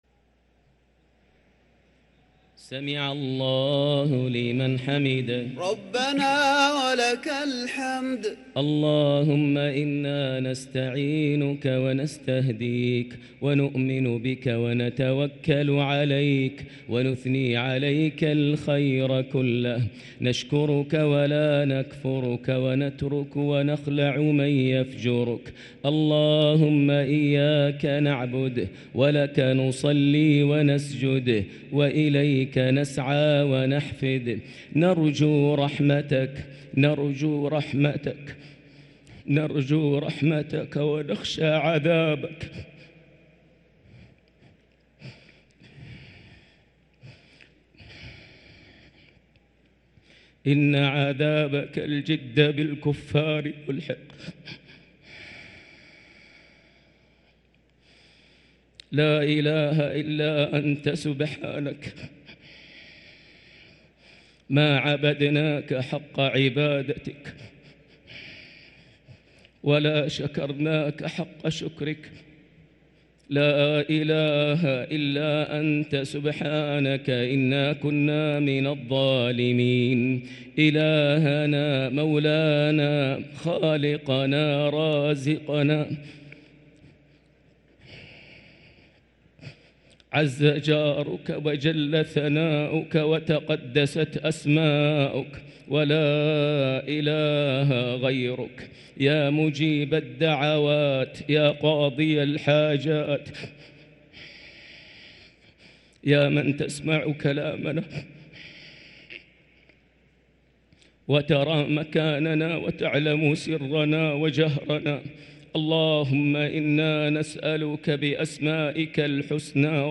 | Dua for the night of 22 Ramadan 1444H > Taraweh 1444H > Taraweeh - Maher Almuaiqly Recitations